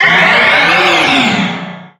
Cri de Méga-Mewtwo Y dans Pokémon HOME.
Cri_0150_Méga_Y_HOME.ogg